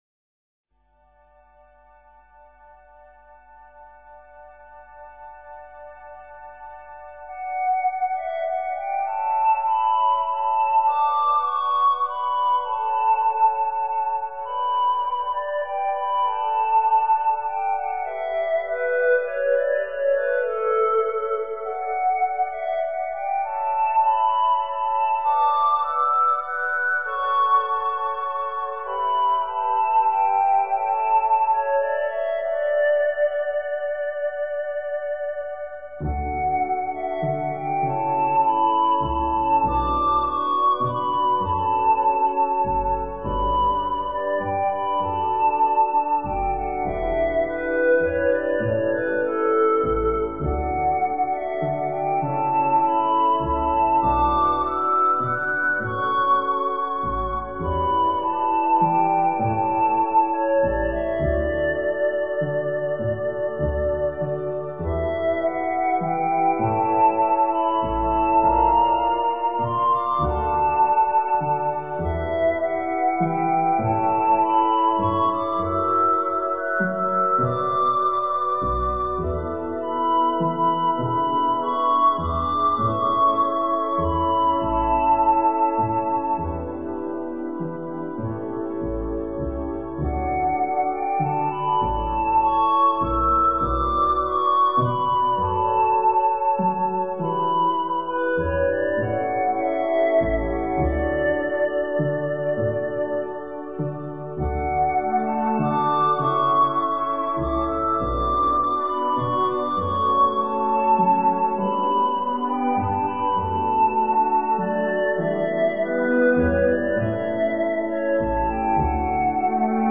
海之记忆--灵性音乐